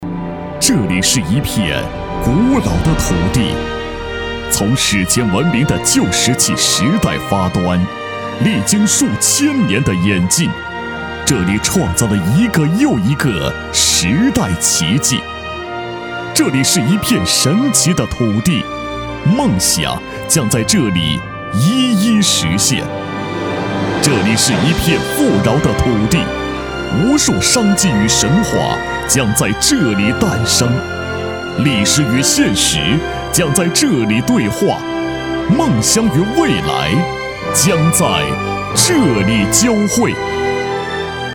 宣传片男111号（青岛保税
稳重磁性 企业宣传配音
大气震撼，磁性稳重，擅长娓娓道来讲述，专题汇报，宣传片，mg动画等题材。